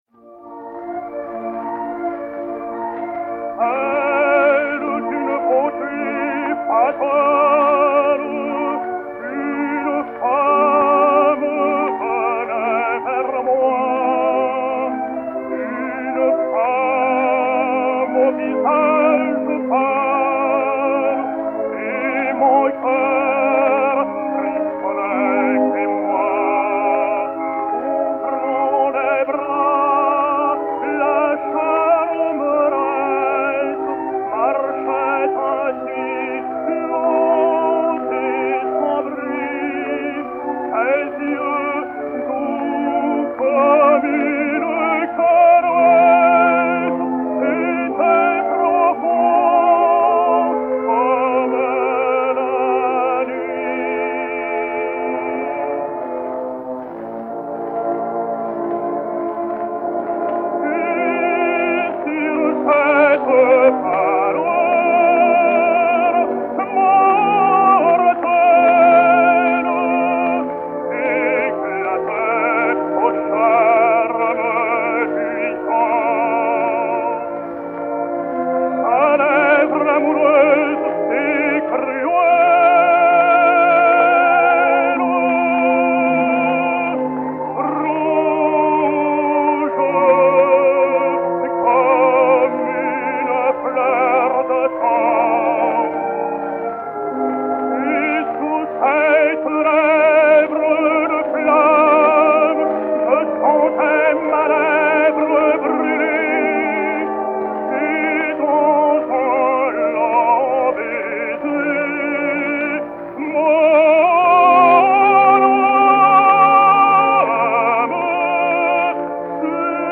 Agustarello Affre (Nour-Eddin) et Orchestre